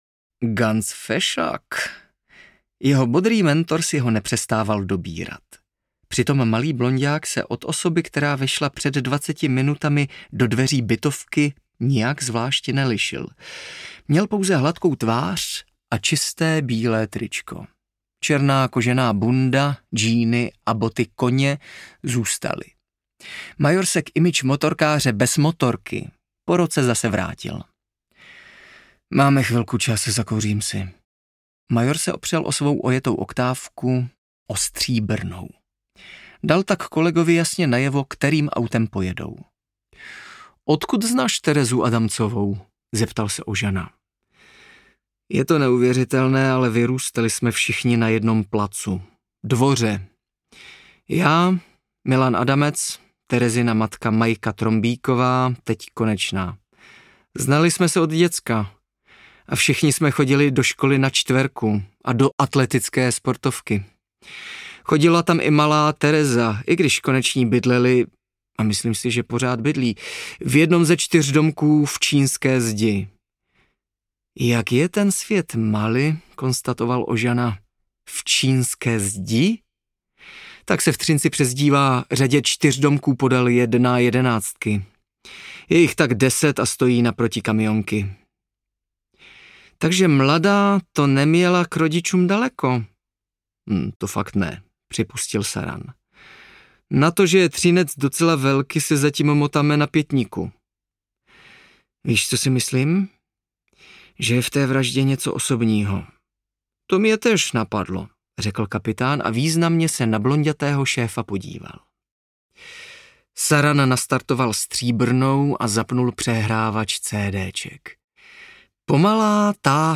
Trujkunt I. – Čtverka audiokniha
Ukázka z knihy
| Vyrobilo studio Soundguru.